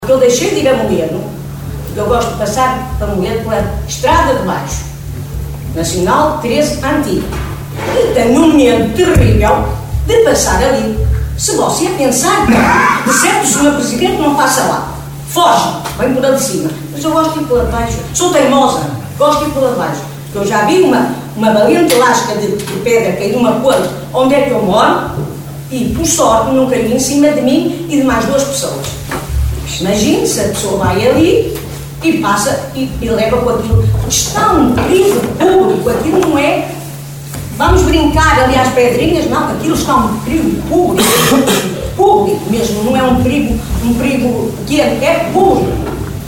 Desta vez o problema não foi abordado por nenhum eleito como tem acontecido até aqui, com inúmeras chamadas de atenção em reuniões do executivo, Assembleias Municipais e de Freguesia, mas sim pela voz de uma munícipe que no período dedicado à intervenção do público chamou a atenção para o perigo que constitui aquela travessia.